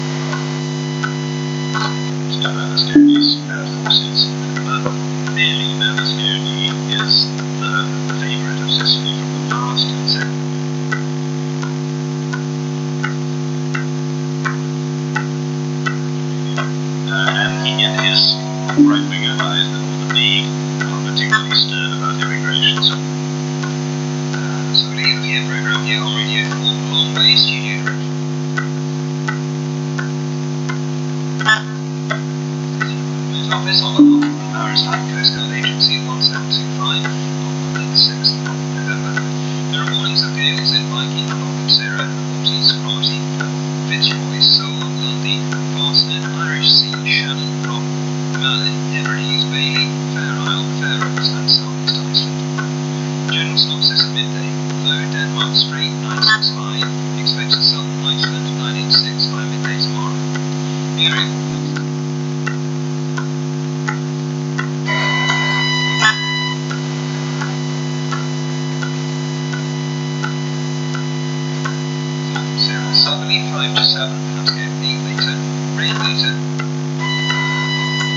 When I place my hands on my mobile phone/induction loop receiver and listen to its magnetic fluctuations, I become an aerial and the set-up picks up a radio station and when I take my hands off the reception fades off.
recording-body-mobile-phone-aerial-6-nov-2017.mp3